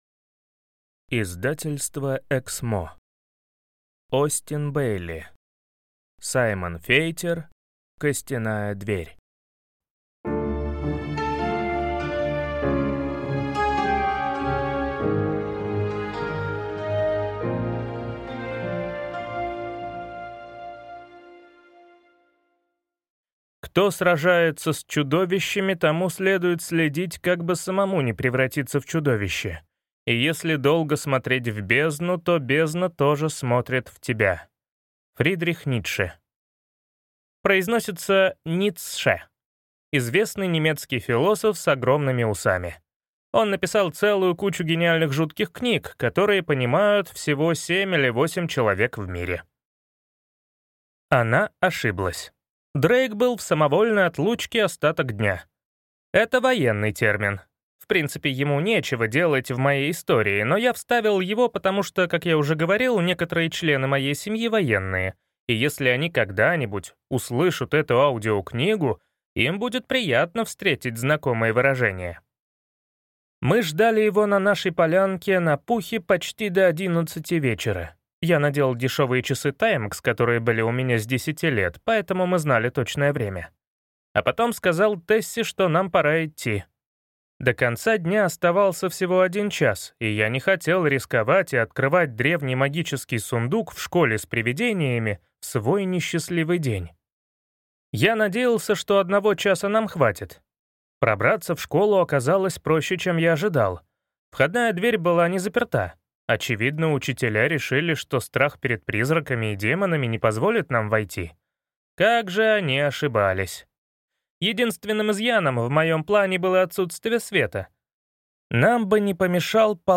Аудиокнига Саймон Фейтер. Костяная дверь | Библиотека аудиокниг
Прослушать и бесплатно скачать фрагмент аудиокниги